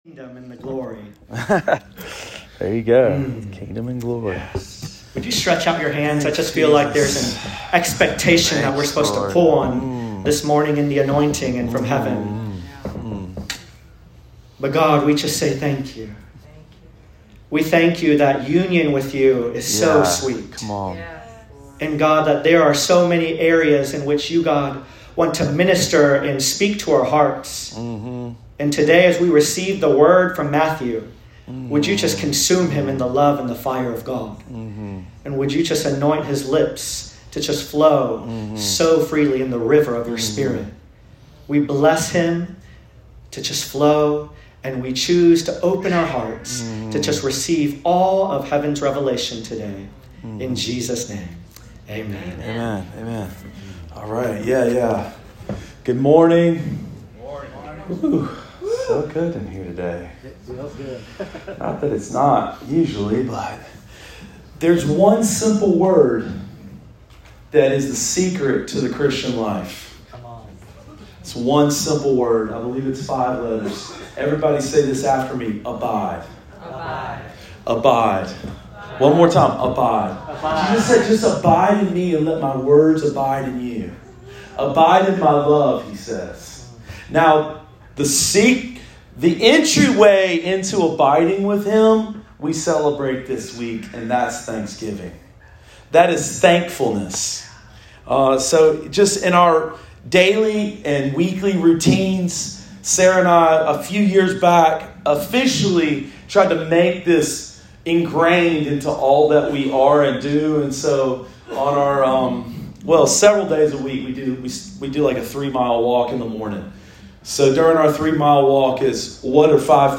Sermon of the Week: 11/20/22 – RiverLife Fellowship Church